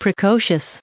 1307_precocious.ogg